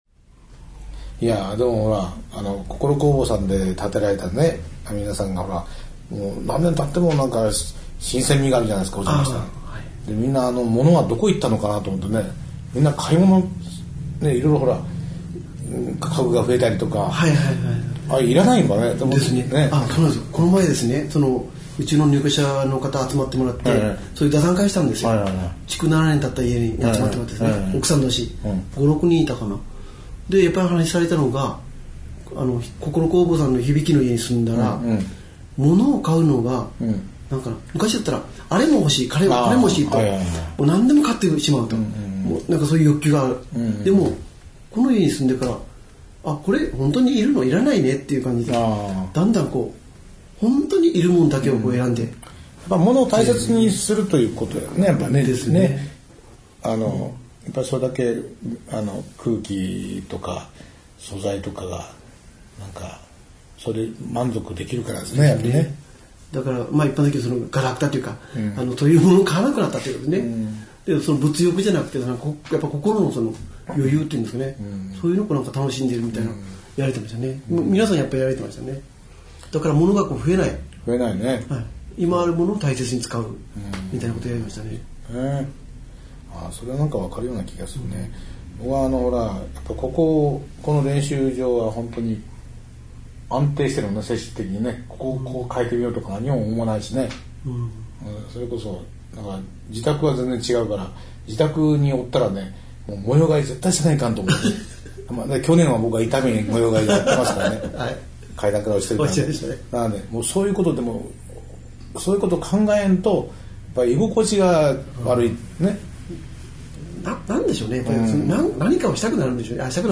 さて、先週に引き続き、ゲストなしのフリートークをお送りしています。